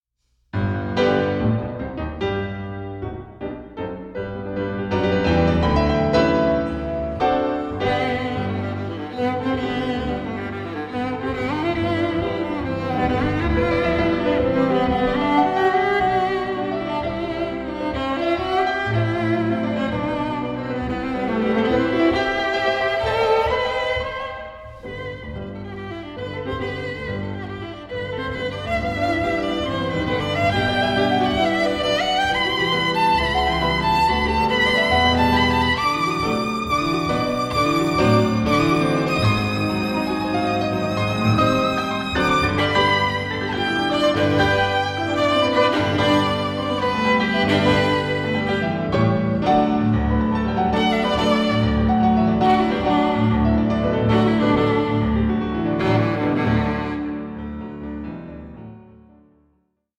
for viola and piano